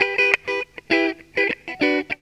GTR 57 EM.wav